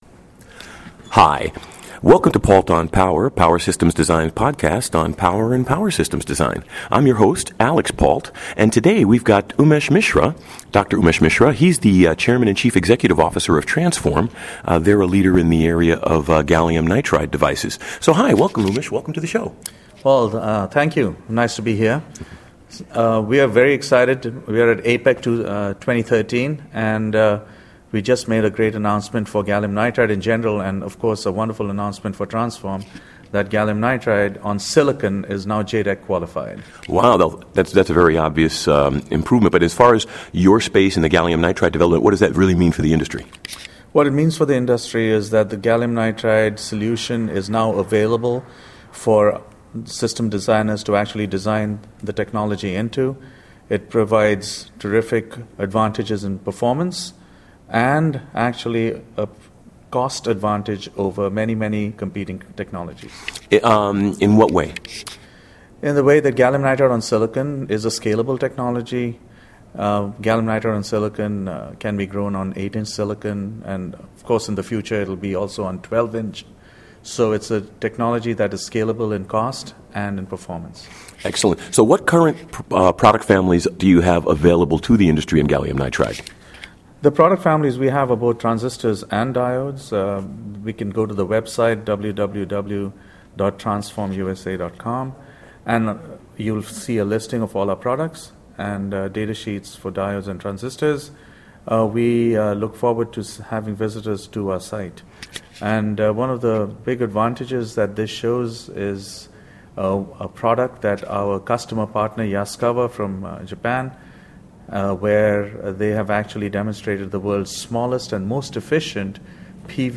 Long Beach CA